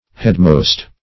Headmost \Head"most`\ (-m[=o]st`), a.